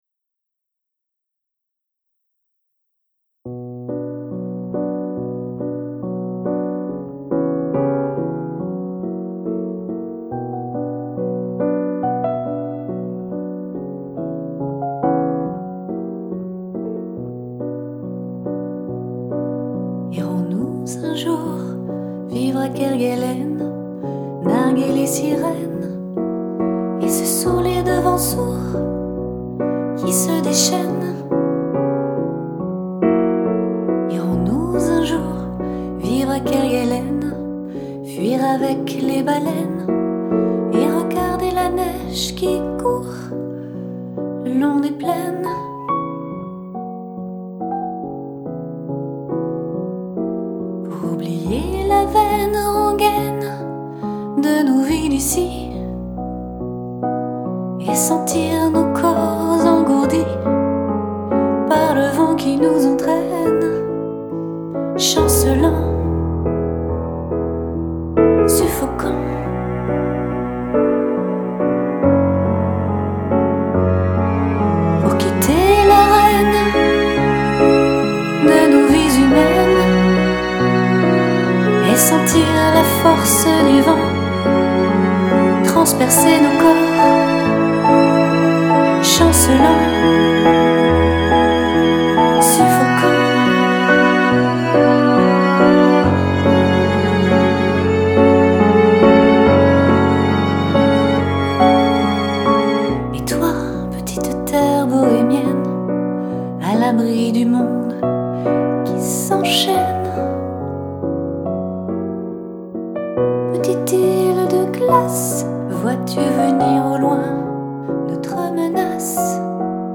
C'est un morceau pour voix et piano, une ballade douce et teintée de mélancolie.